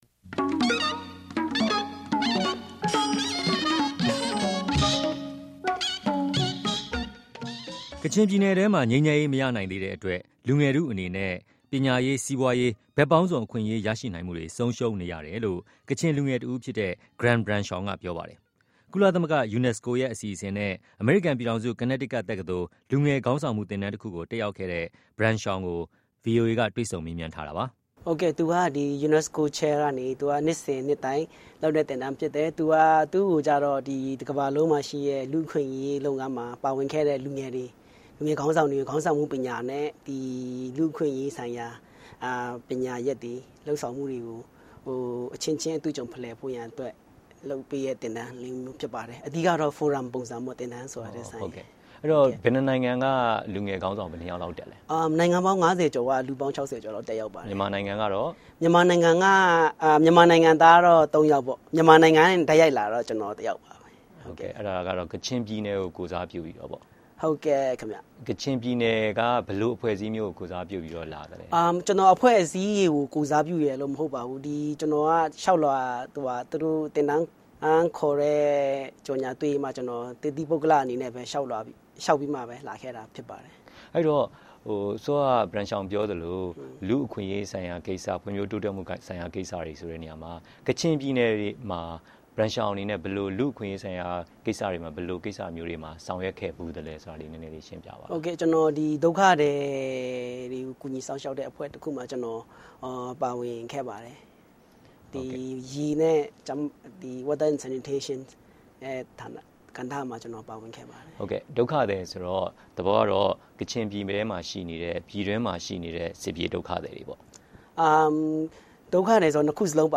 ကချင်လူငယ်ခေါင်းဆောင် နဲ့ တွေ့ဆုံမေးမြန်း